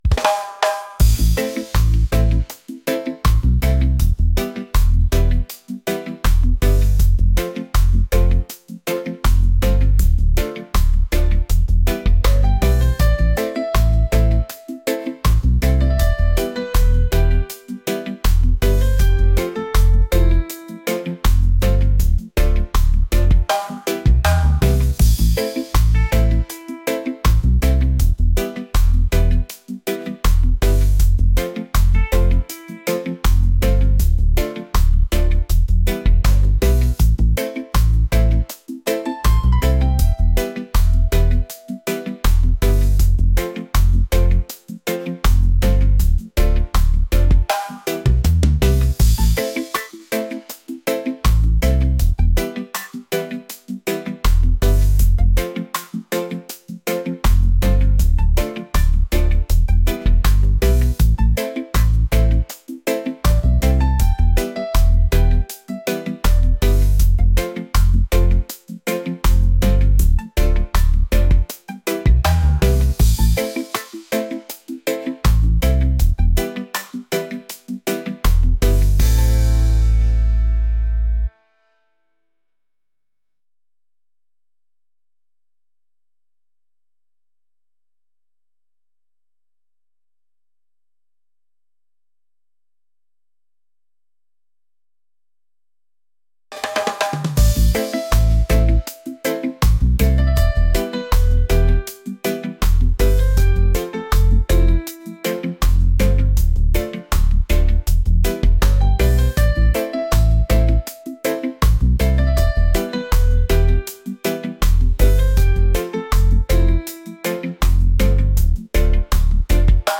laid-back | reggae | romantic